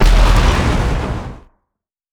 Grenade1Short.wav